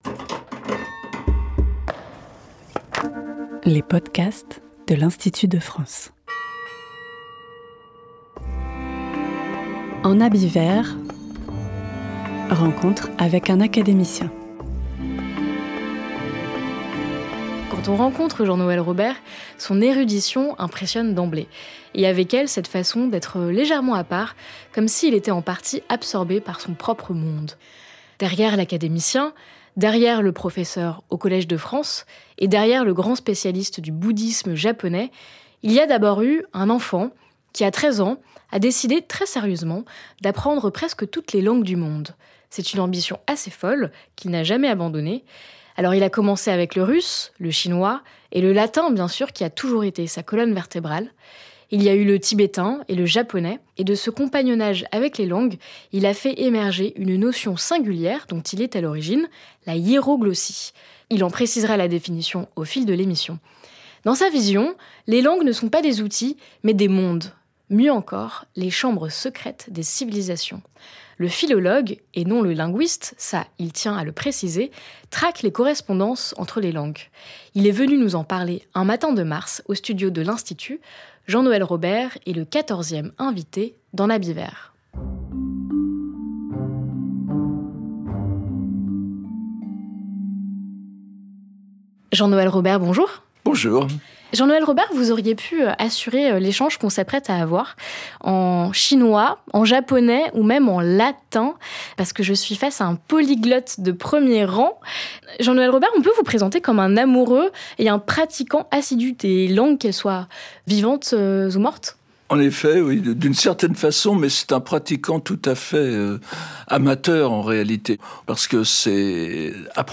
L’entretien explore également les méthodes d’apprentissage, l’importance de la lecture de textes fondamentaux, ainsi que les passerelles entre les langues, notamment entre le chinois et le japonais.